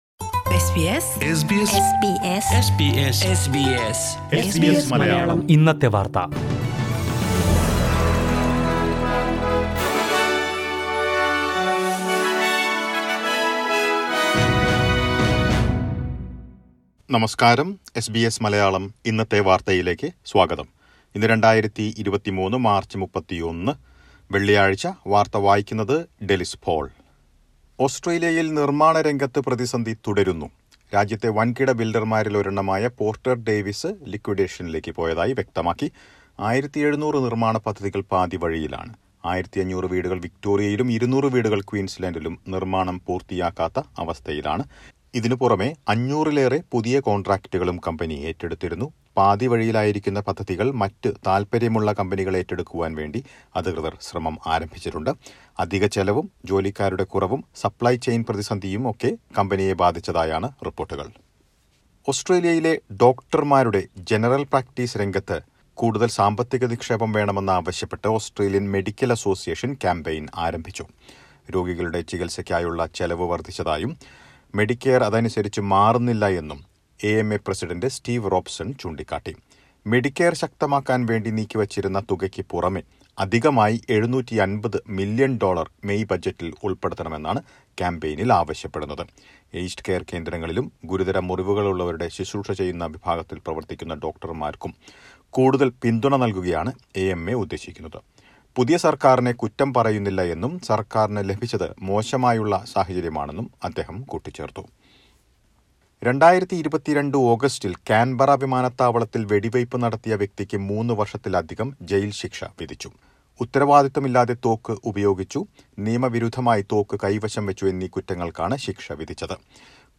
2023 മാർച്ച് 31ലെ ഓസ്ട്രേലിയയിലെ ഏറ്റവും പ്രധാന വാർത്തകൾ കേൾക്കാം...